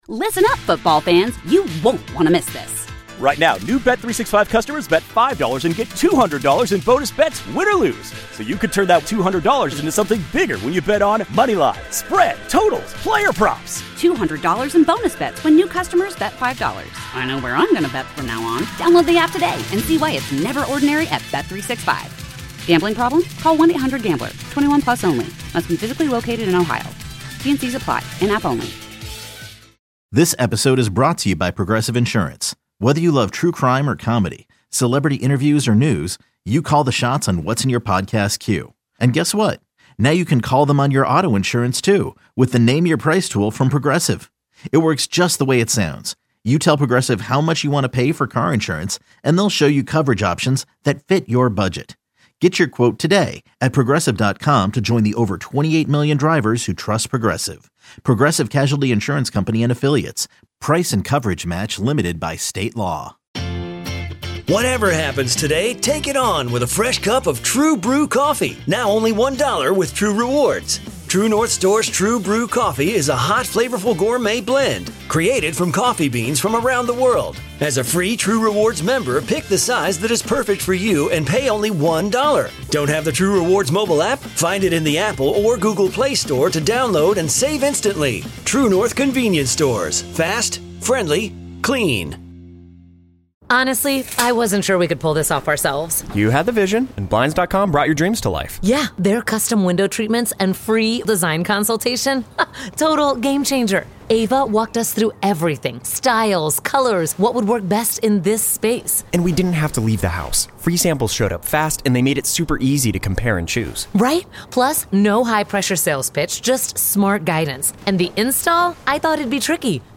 Also hear players coaches and our Football insiders.